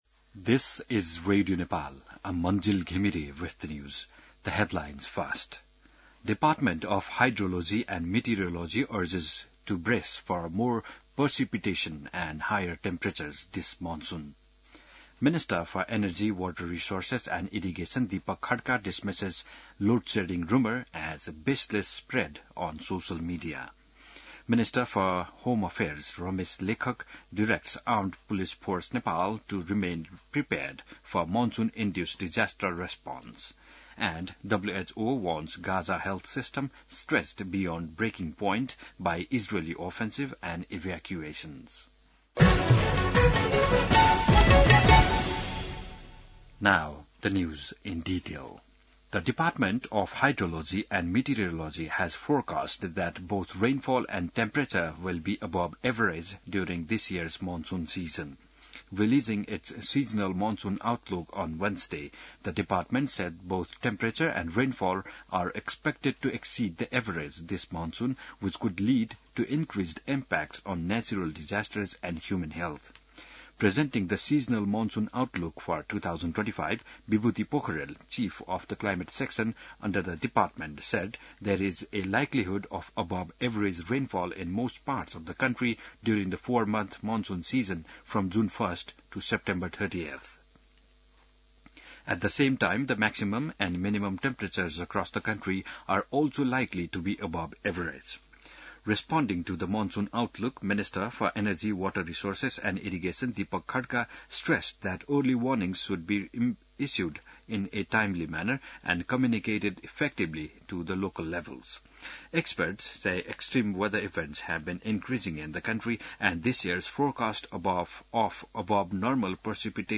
बिहान ८ बजेको अङ्ग्रेजी समाचार : ८ जेठ , २०८२